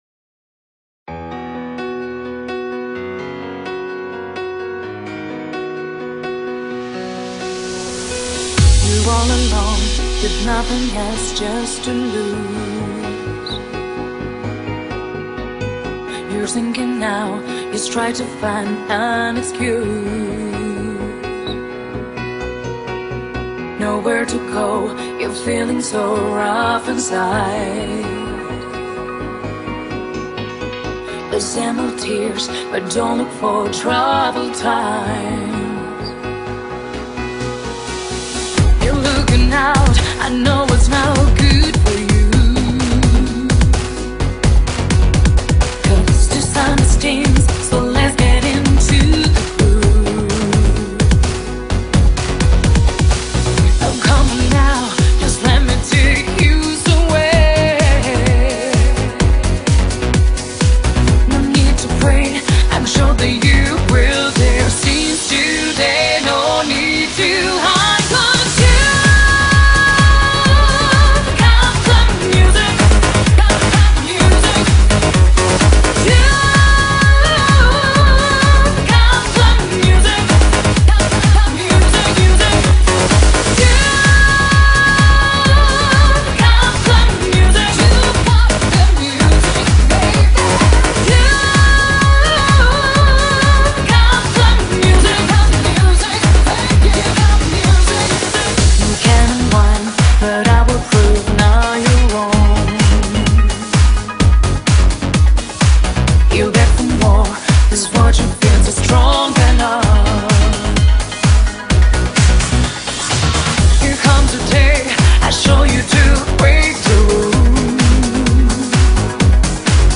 ◎新世代西班牙浩室舞曲天后，第二張全英文霸氣大碟